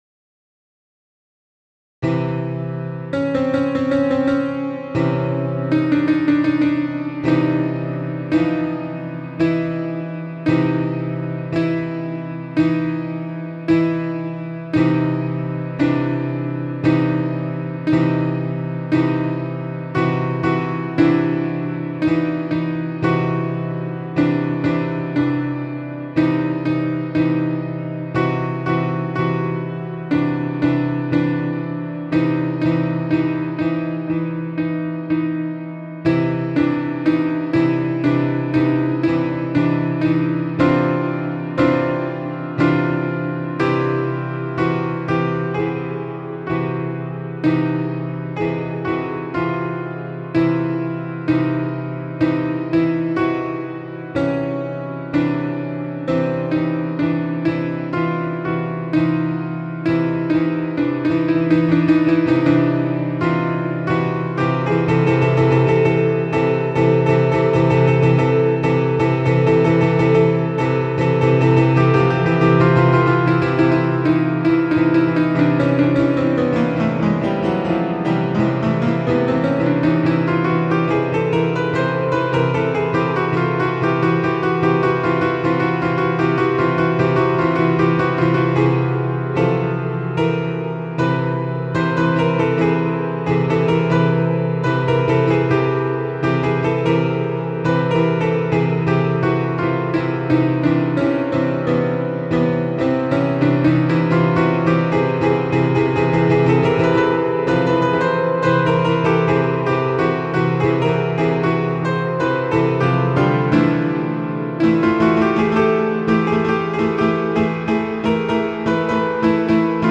Импровизация и техника игры
Покритикуйте пожалуйста эти импровизации и технику игры: